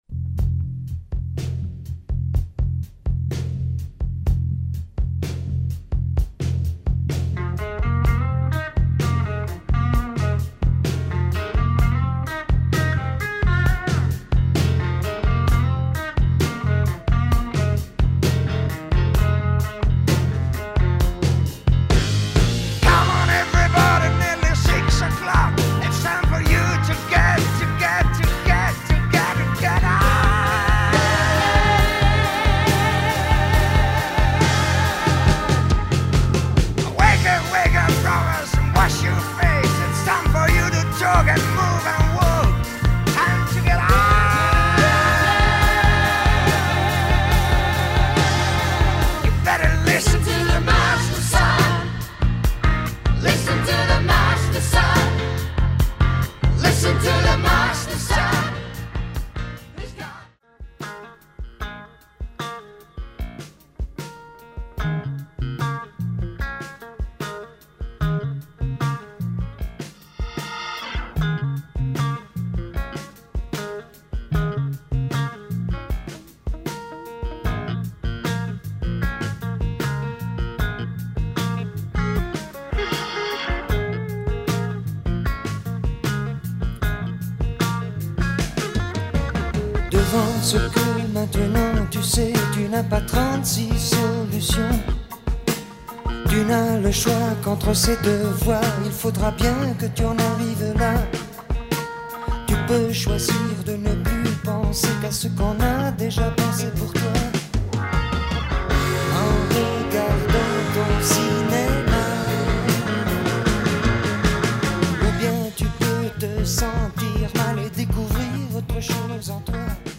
Excellent jazz and prog sounds
strings arrangements